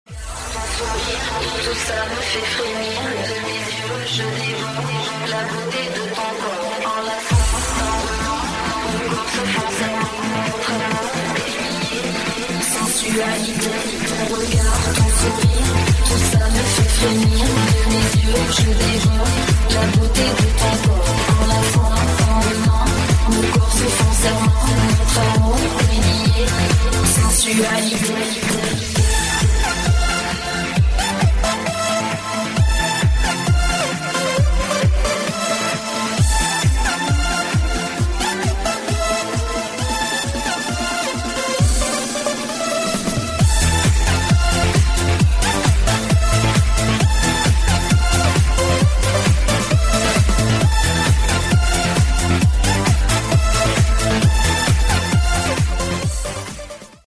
• Качество: 128, Stereo
dance
электронная музыка
Dance Pop
красивый женский голос